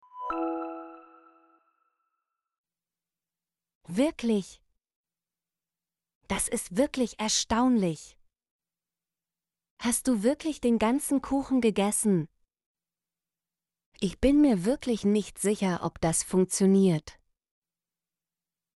wirklich - Example Sentences & Pronunciation, German Frequency List